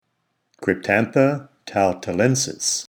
Pronunciation/Pronunciación:
Cryp-tán-tha  tal-ta-lén-sis